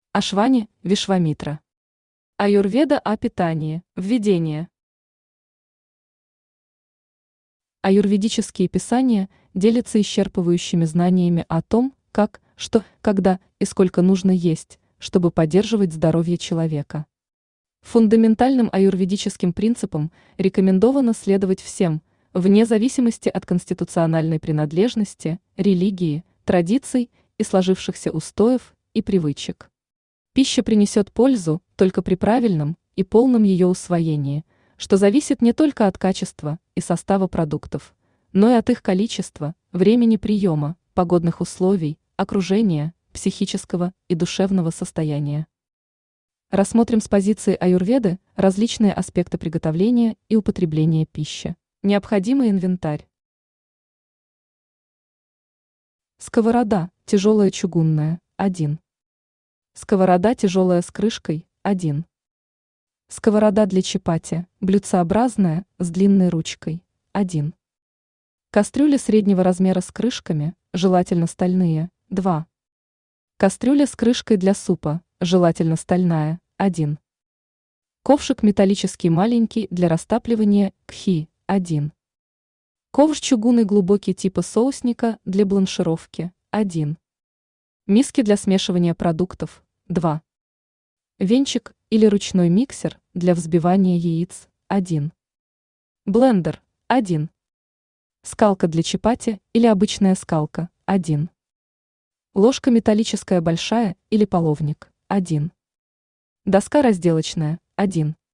Аудиокнига Аюрведа о питании | Библиотека аудиокниг
Aудиокнига Аюрведа о питании Автор Ашвани Вишвамитра Читает аудиокнигу Авточтец ЛитРес.